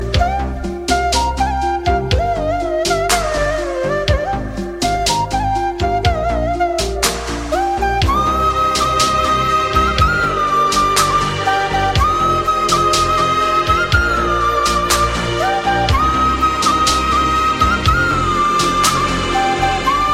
Guitar Ringtones